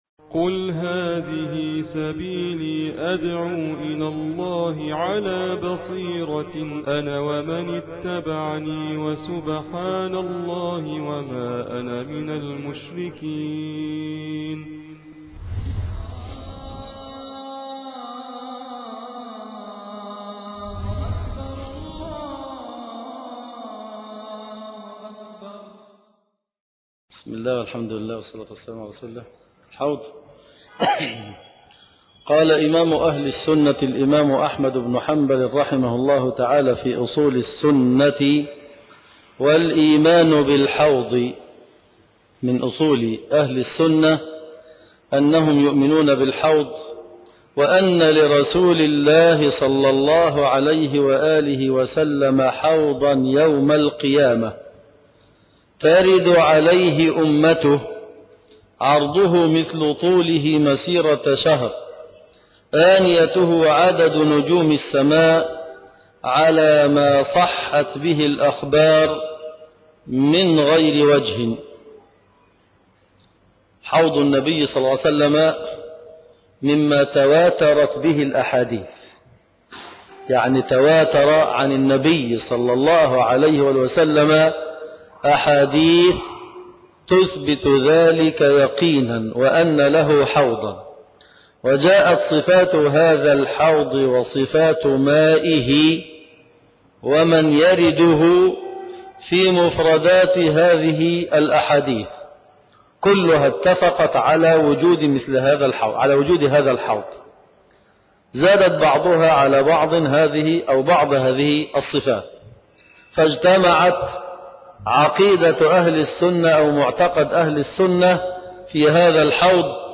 الدرس ( 10) شرح أصول السنة للإمام أحمد